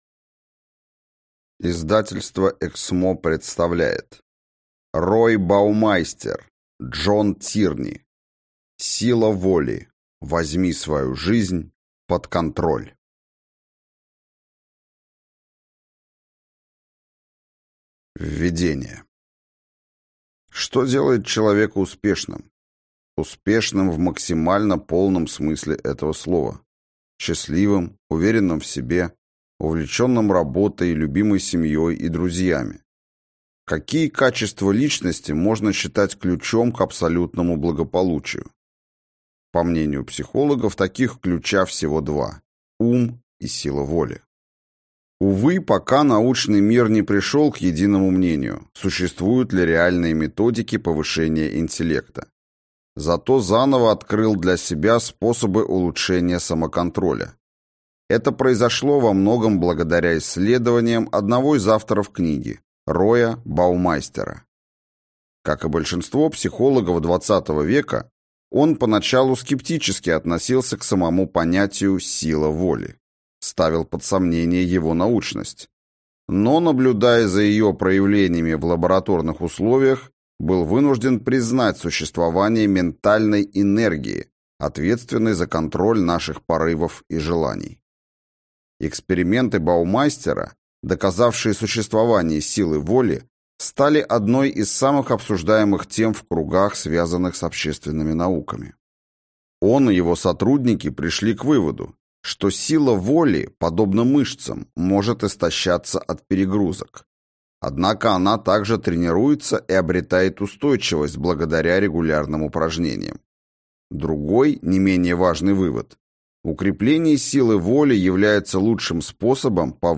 Аудиокнига Сила воли. Возьми свою жизнь под контроль | Библиотека аудиокниг